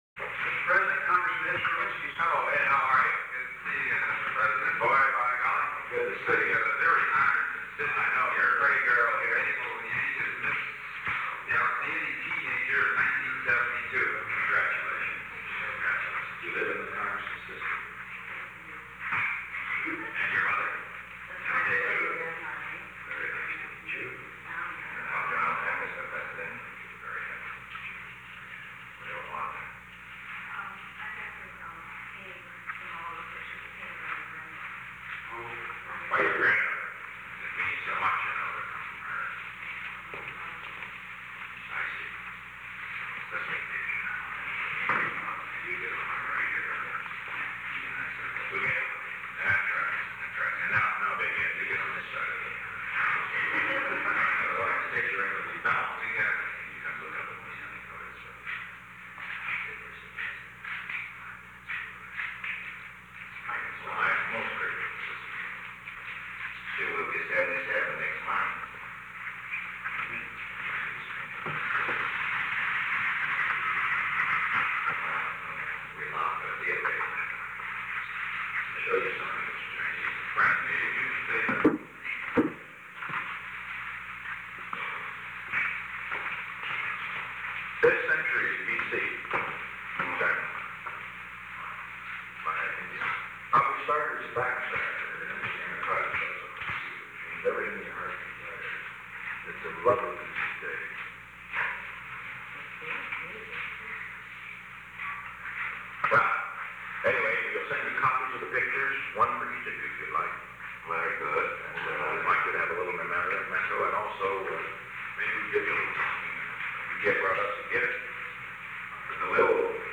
Secret White House Tapes
Location: Oval Office